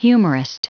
Prononciation du mot humorist en anglais (fichier audio)
Prononciation du mot : humorist